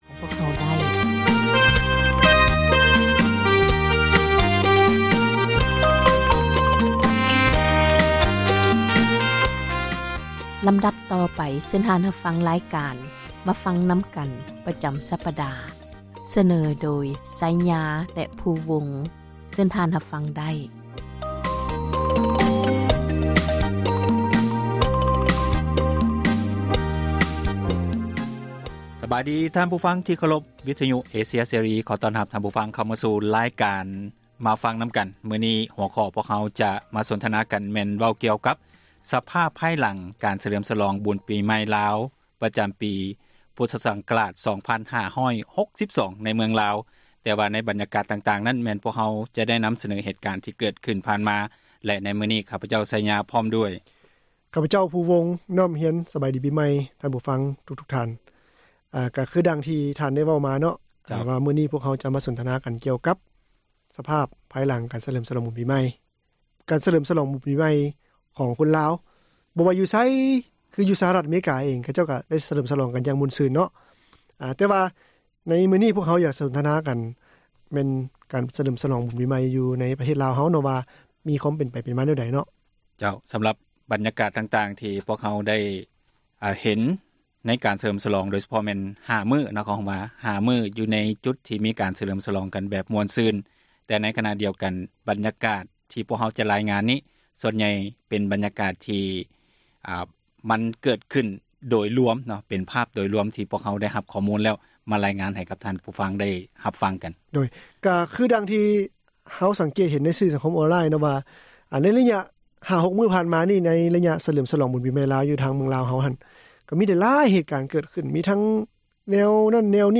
"ມາຟັງນຳກັນ" ແມ່ນຣາຍການສົນທະນາ ບັນຫາສັງຄົມ ທີ່ຕ້ອງການ ພາກສ່ວນກ່ຽວຂ້ອງ ເອົາໃຈໃສ່ແກ້ໄຂ, ອອກອາກາດ ທຸກໆວັນອາທິດ ເວລາ 6:00 ແລງ ແລະ ເຊົ້າວັນຈັນ ເວລາ 7:00